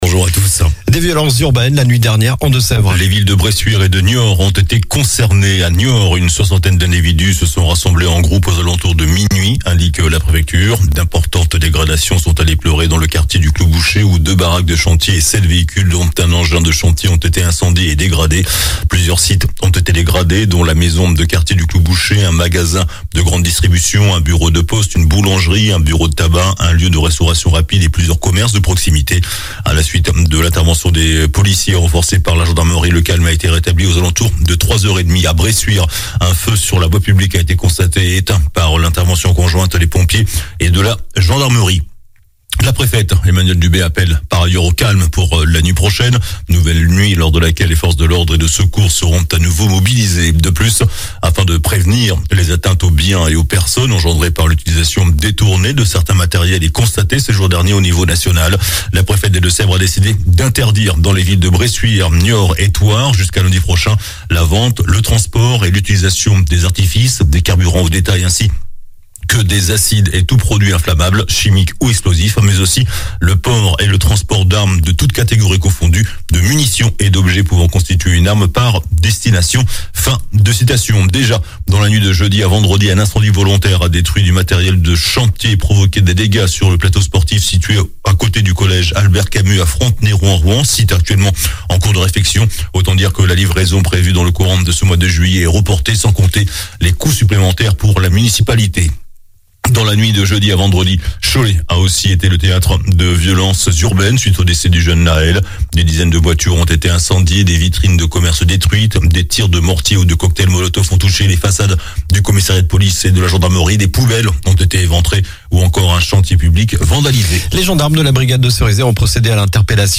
JOURNAL DU SAMEDI 01 JUILLET